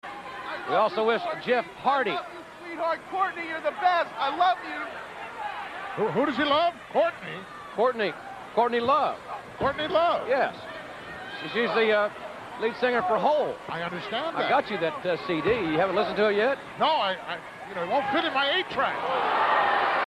Besides, I’d rather spend our time today discussing how Jim Ross educated Gorilla Monsoon about Courtney Love and Hole! Yes, kids this was a thing that actually happened during this match.